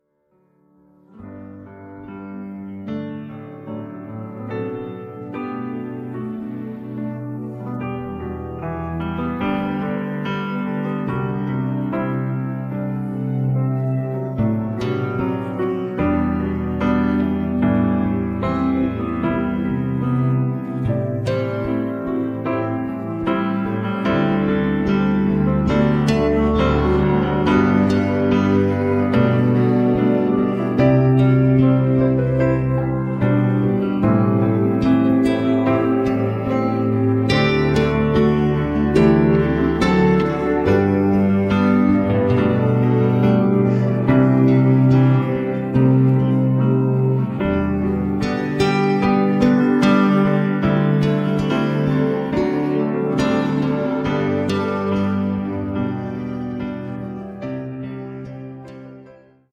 음정 -1키 4:17
장르 가요 구분 Voice Cut